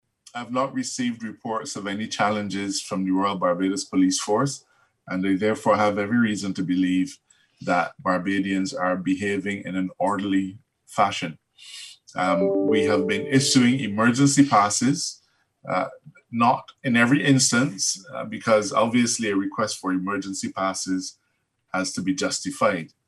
He also told a COVID-19 media update that following requests and consultation with health officials, government has expanded the number of minimarts in the published pool.